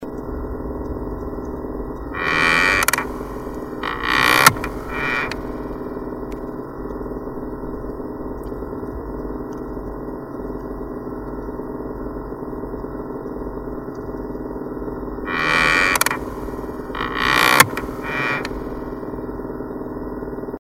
knarrihandskfacket.MP3